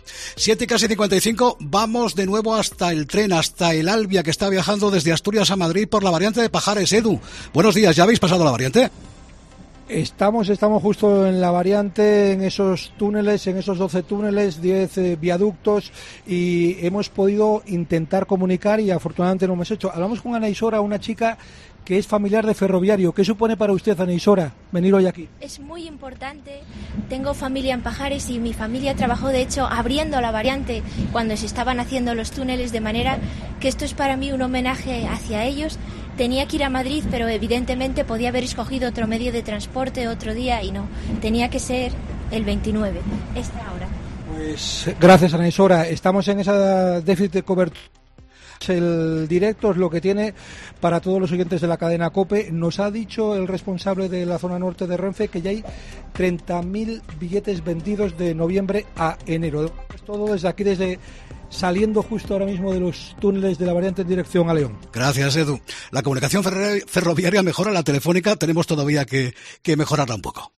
COPE Asturias se ha subido al primer tren comercial que cruza los nuevos túneles de Pajares: salió de Gijón a las 6:30 horas y hemos vivido el trayecto con alguno de los viajeros